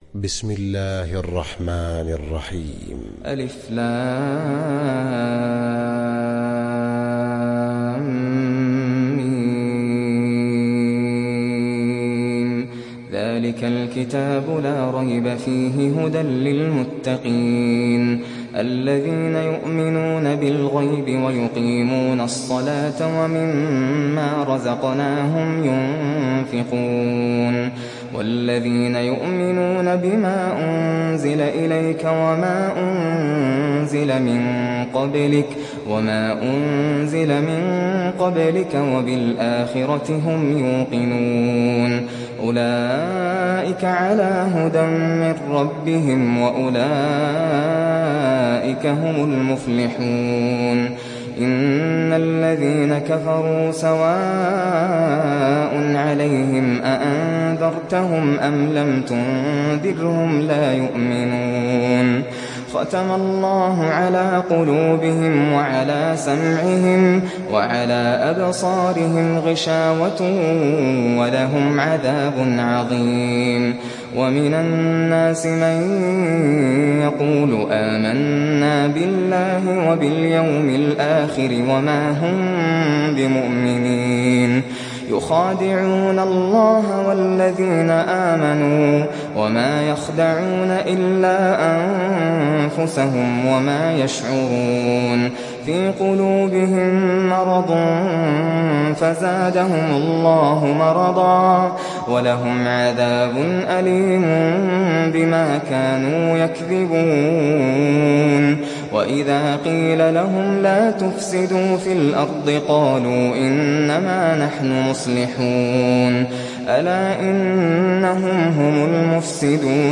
تحميل سورة البقرة mp3 بصوت ناصر القطامي برواية حفص عن عاصم, تحميل استماع القرآن الكريم على الجوال mp3 كاملا بروابط مباشرة وسريعة